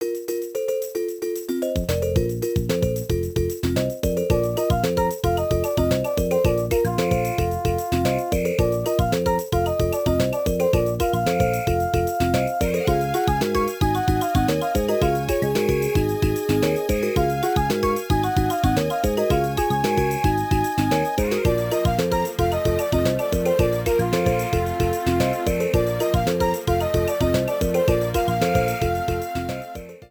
Ripped from game data, then trimmed in Audacity